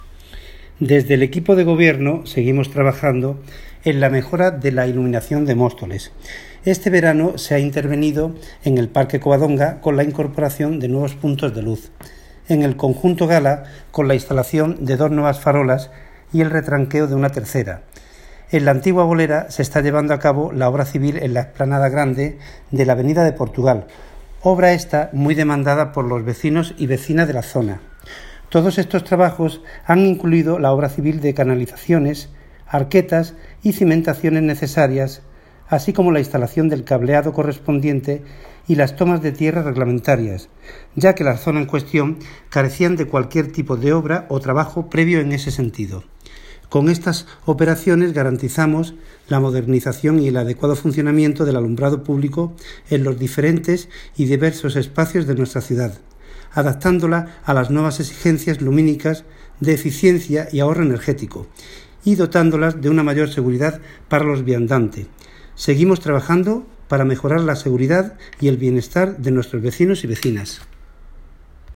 Audio - Agustín Martín (Concejal de Obras, Infraestructuras y Mantenimiento de vías públicas) Sobre puntos de luz